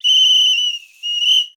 Foley Sports / Whistle / Field Hockey Goal.wav
Field Hockey Goal.wav